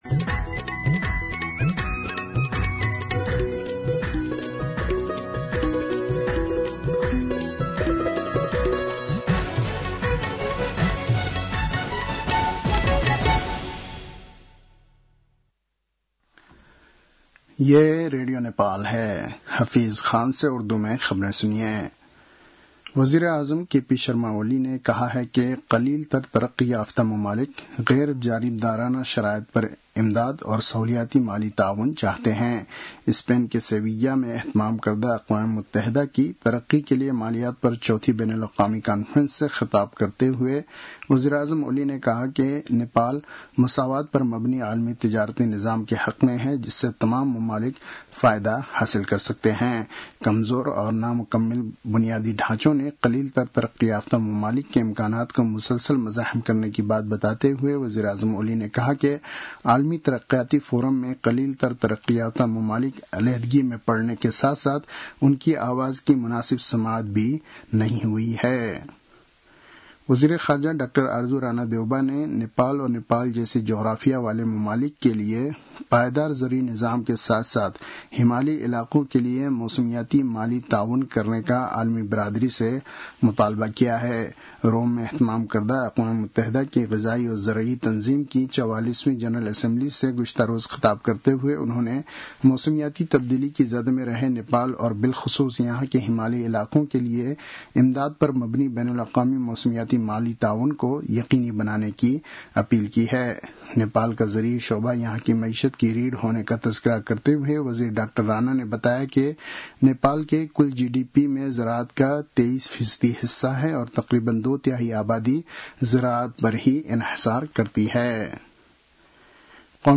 An online outlet of Nepal's national radio broadcaster
उर्दु भाषामा समाचार : १७ असार , २०८२